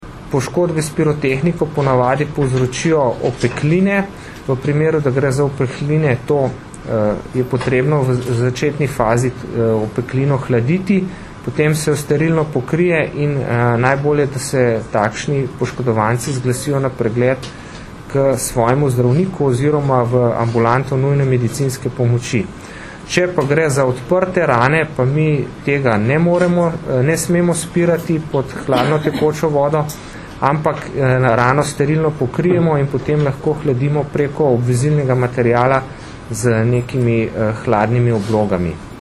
Zvočni posnetek izjave asist. mag.